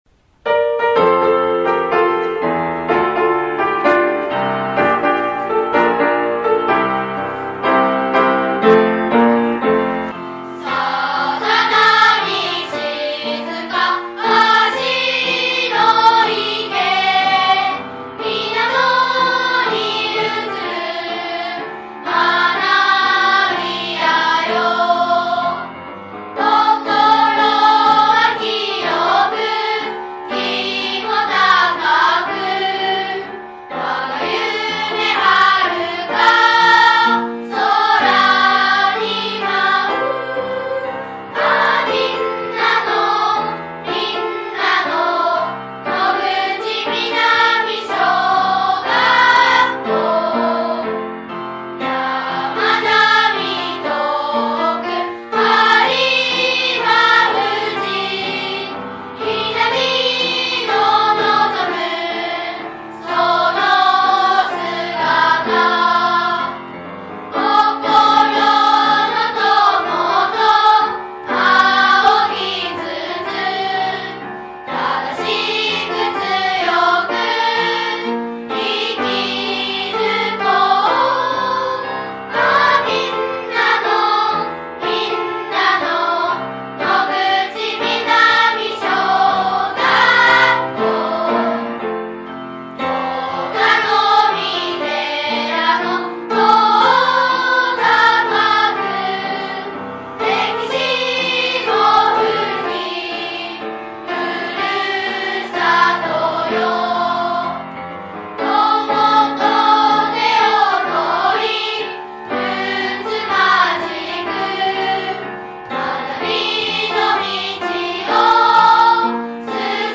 校歌
kouka.mp3